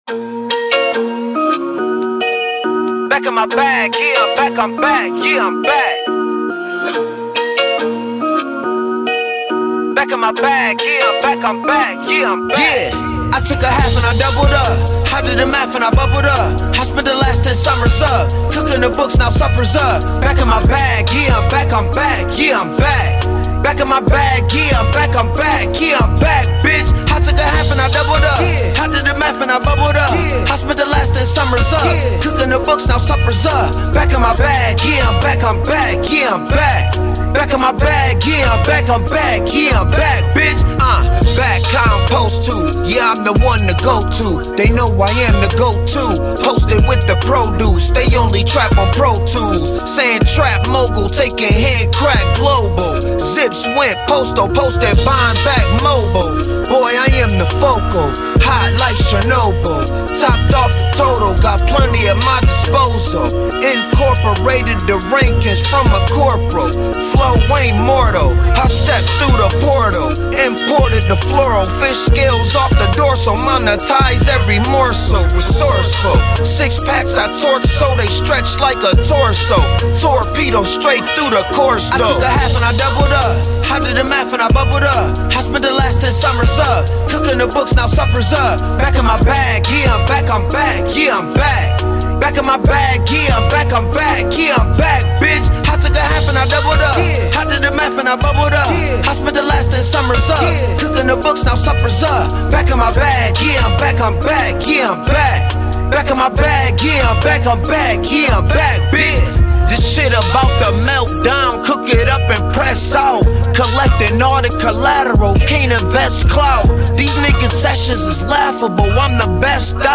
Hiphop
Description : Street Intellectual Trap Anthem